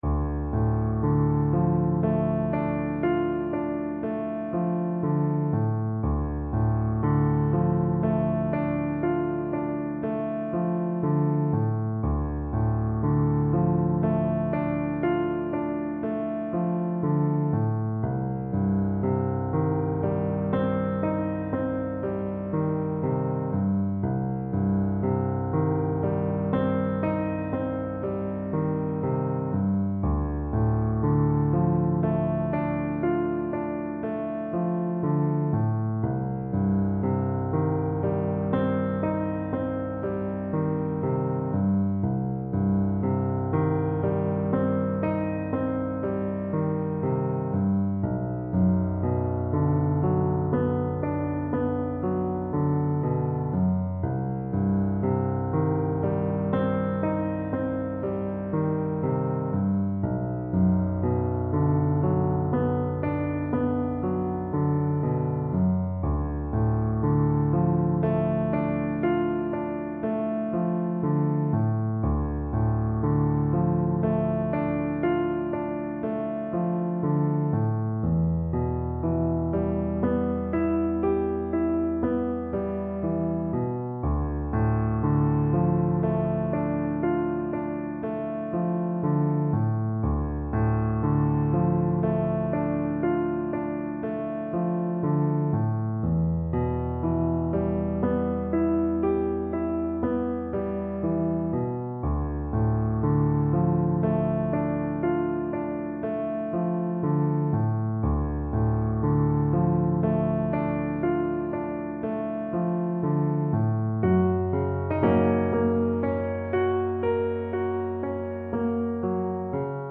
C major (Sounding Pitch) (View more C major Music for Flute )
Lento
Classical (View more Classical Flute Music)